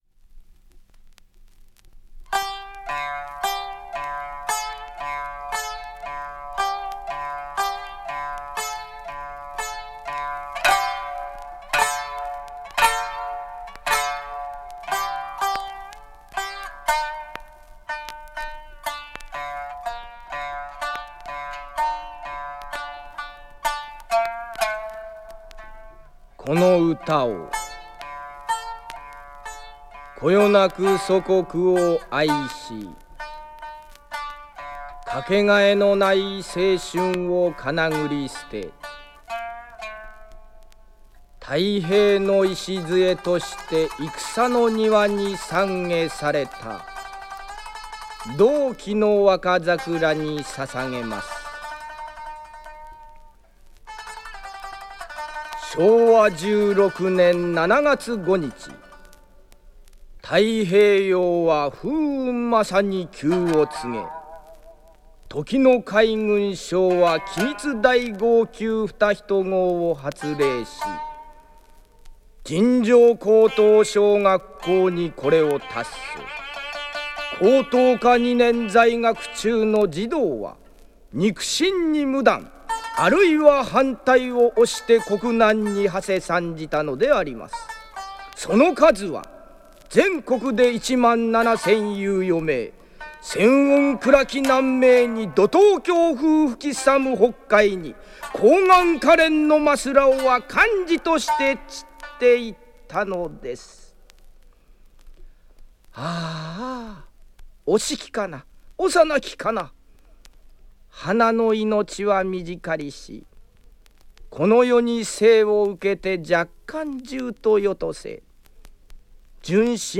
いずれも昭和30-40年代頃の古い録音です。
収録時のノイズ等お聞き苦しい箇所はご容赦願います。